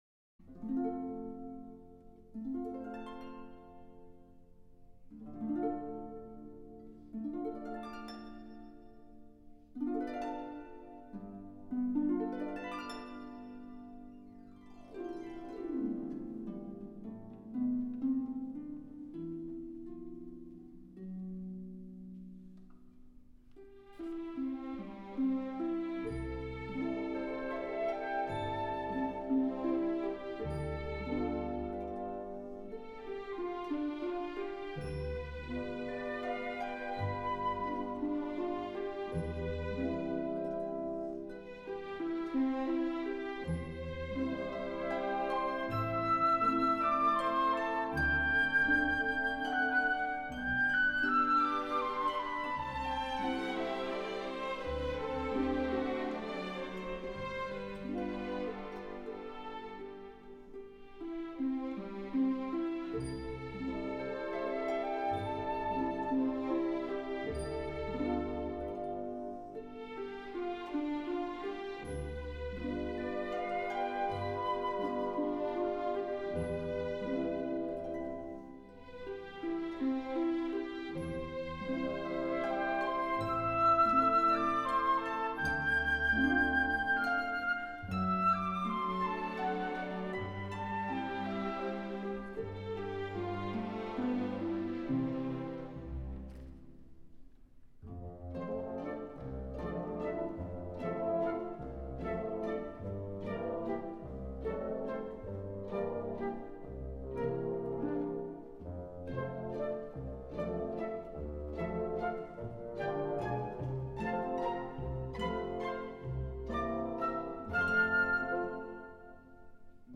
• A harp variation that Drigo composed for Anna Pavlova’s performance as Queen Nisia is traditionally performed today in the Paquita Grand Pas Classique.
23-paquita_-variation-3_-tempo-di-va.m4a